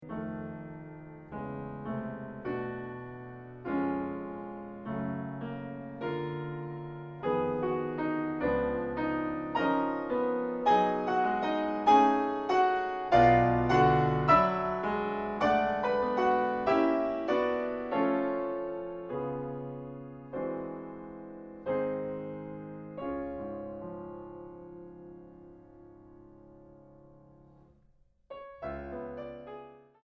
Piano Bösendorfer 290 Imperial.